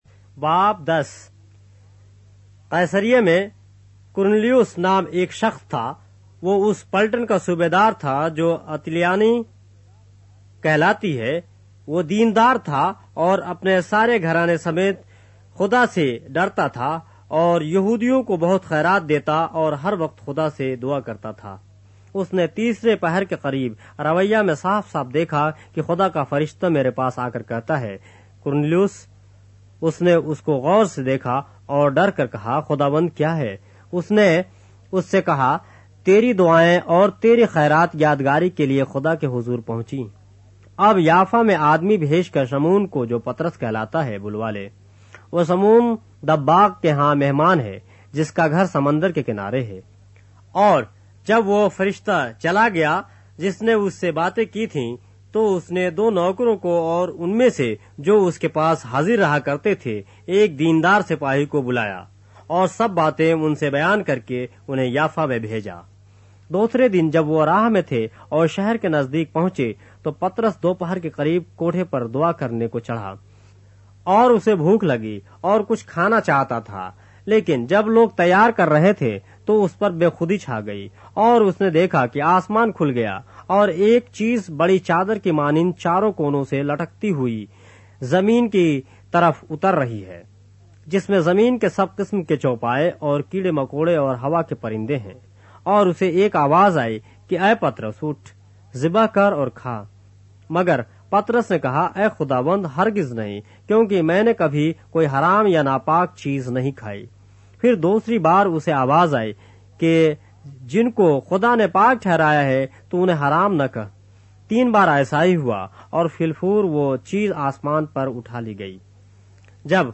اردو بائبل کے باب - آڈیو روایت کے ساتھ - Acts, chapter 10 of the Holy Bible in Urdu